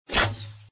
bow.mp3